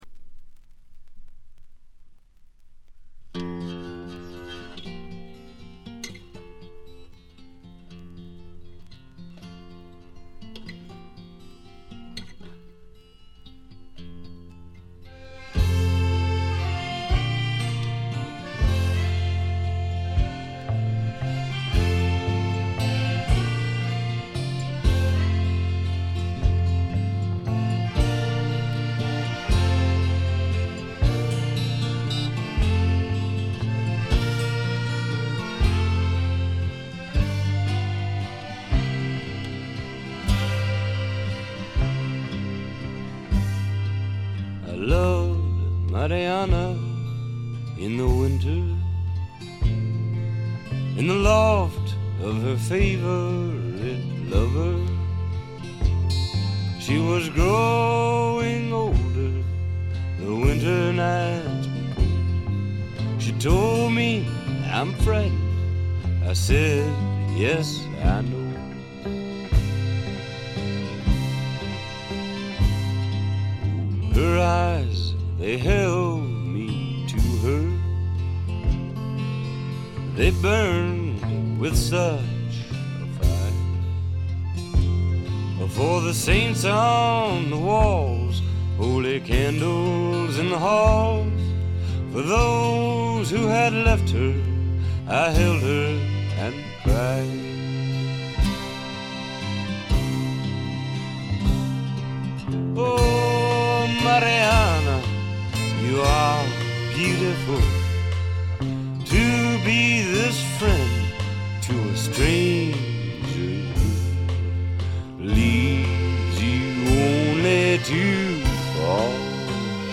B1中盤とB2中盤にプツ音1回。
試聴曲は現品からの取り込み音源です。